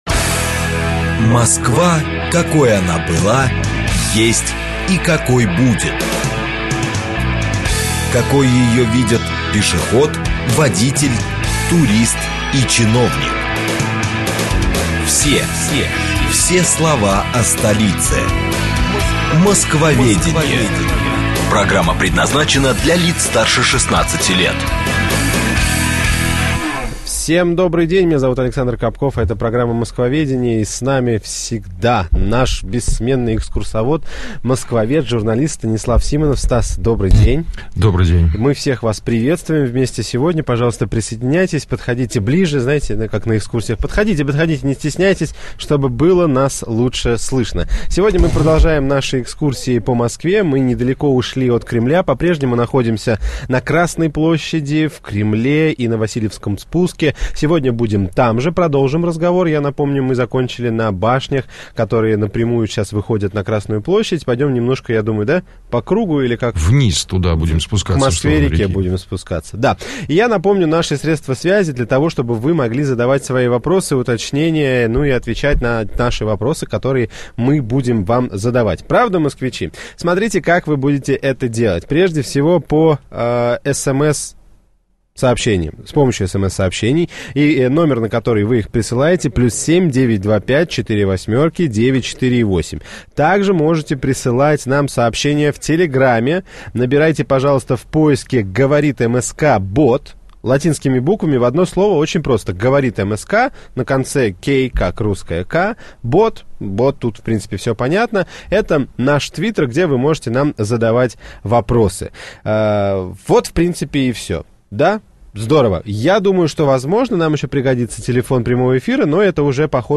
Аудиокнига Васильевский спуск | Библиотека аудиокниг